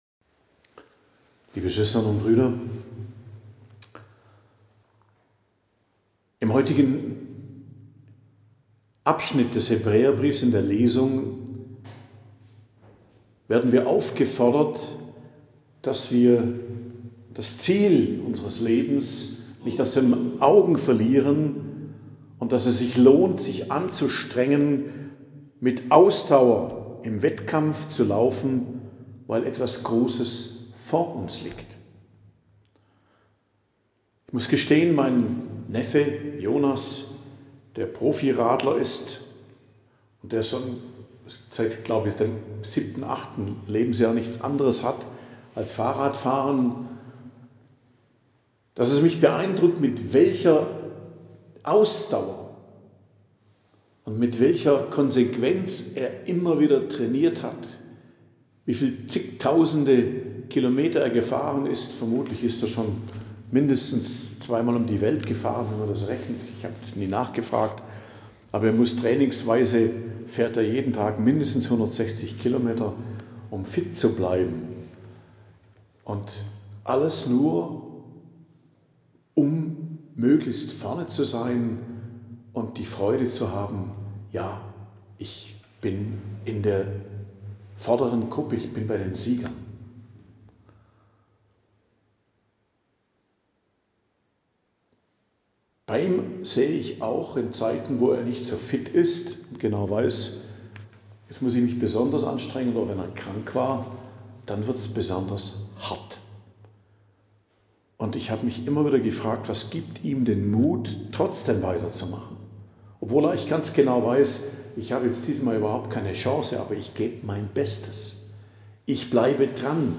Predigt am Dienstag der 4. Woche i.J. 4.02.2025 ~ Geistliches Zentrum Kloster Heiligkreuztal Podcast